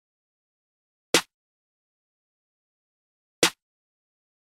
陷阱序列1小号
Tag: 105 bpm Trap Loops Drum Loops 787.67 KB wav Key : Unknown